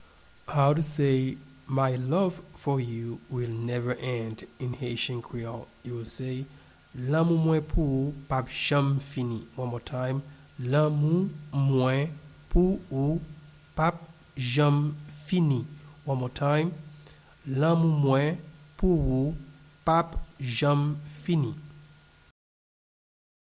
Pronunciation and Transcript:
My-love-for-you-will-never-end-in-Haitian-Creole-Lanmou-mwen-pou-ou-p-ap-janm-fini-pronunciation.mp3